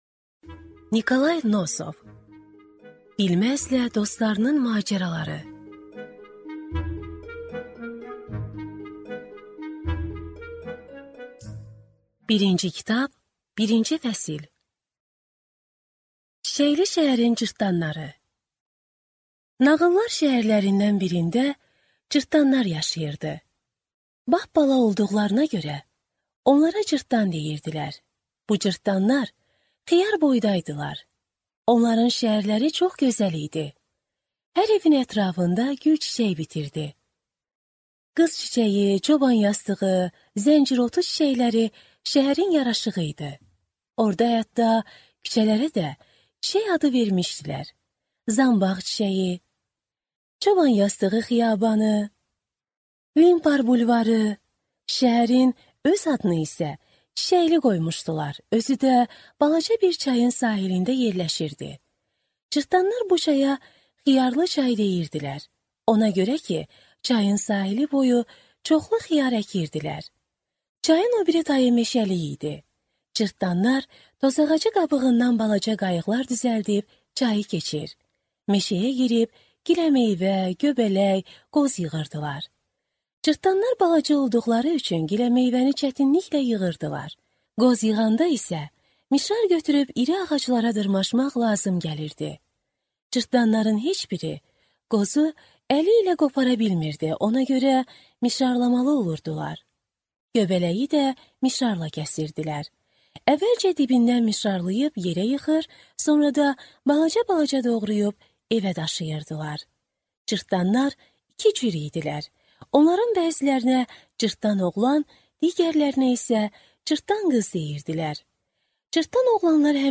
Аудиокнига Bilməzlə dostlarının macəraları | Библиотека аудиокниг